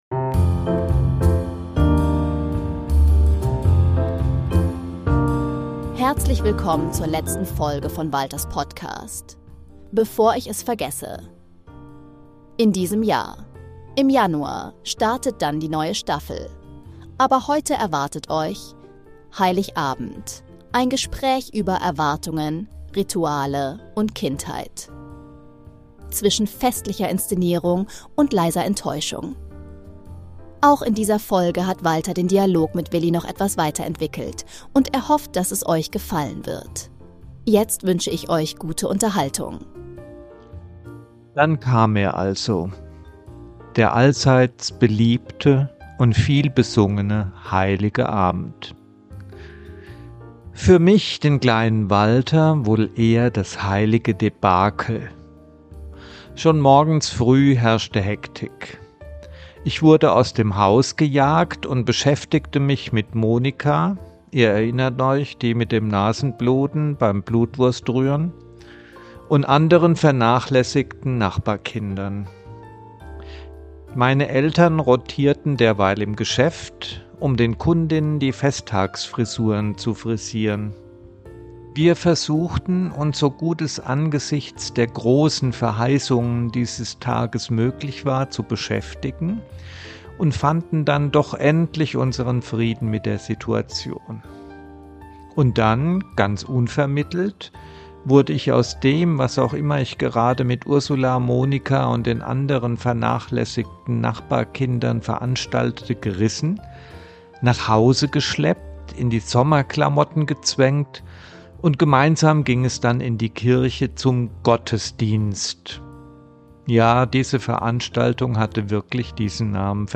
Beschreibung vor 3 Monaten In dieser Folge lese ich meine persönliche Weihnachtsgeschichte: Heiligabend zwischen Kirche, Familienritualen, Hoffnung, Enttäuschung und den kleinen Momenten echter Magie. Anschließend spreche ich im vertiefenden Dialog darüber, warum dieses Fest so oft an seinen eigenen Versprechen scheitert — und welche Sehnsüchte aus Kindheitstagen bis heute nachwirken. Ein ehrliches, reflektierendes Gespräch über Weihnachten und die Frage, was es für uns bedeutet.